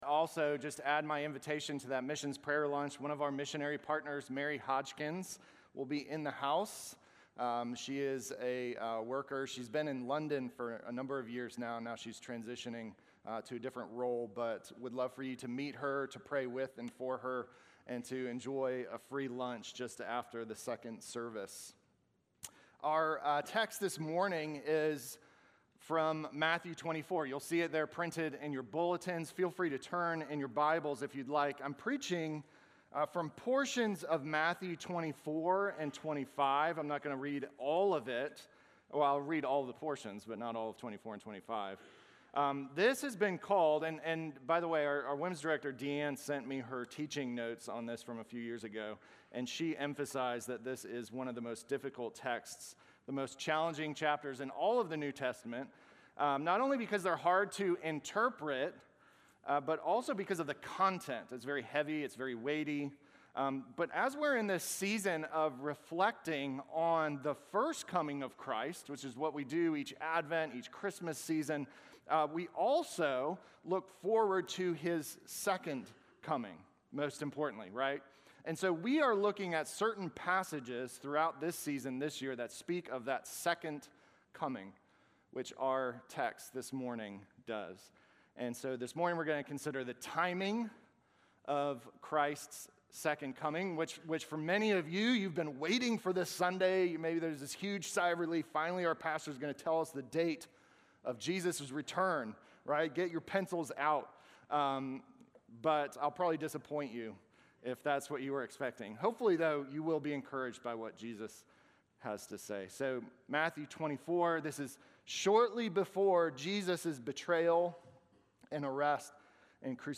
Sermon from December 14